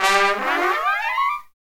Index of /90_sSampleCDs/Roland LCDP06 Brass Sections/BRS_Tpts FX menu/BRS_Tps FX menu